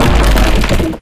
Door2.ogg